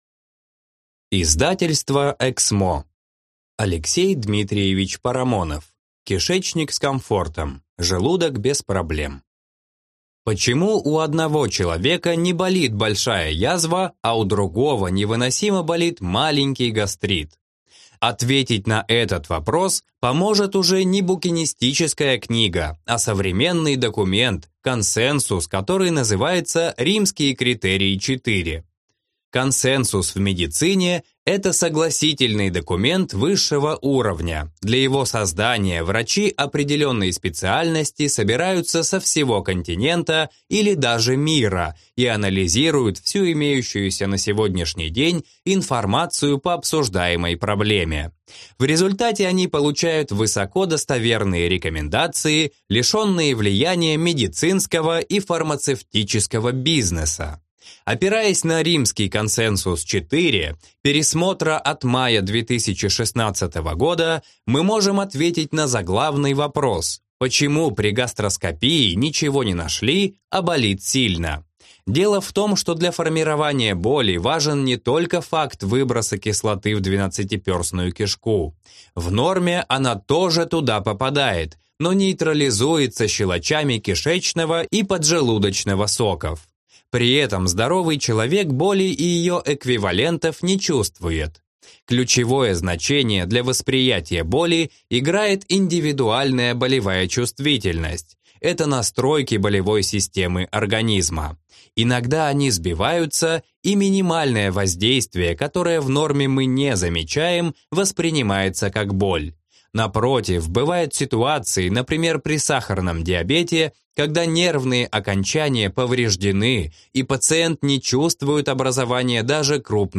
Аудиокнига Кишечник с комфортом, желудок без проблем | Библиотека аудиокниг